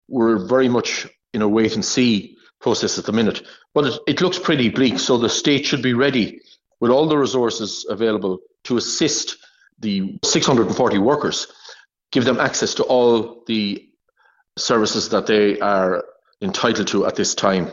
Labour enterprise spokesperson, George Lawlor says support services should be ready to step in if needed: